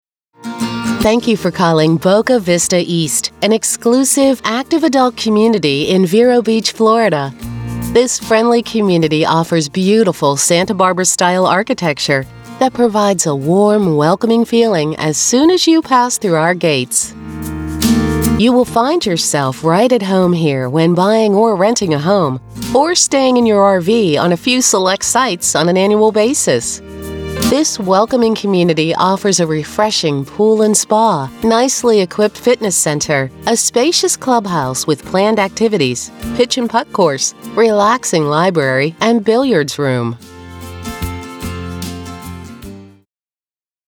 American Female Voice Talent for On Hold and Auto Attendant Audio
Accent: North American/Midwestern
Tone / Style: Warm, friendly, bright, soothing, approachable, fun, sincere, conversational, sophisticated, professional, cheerful, natural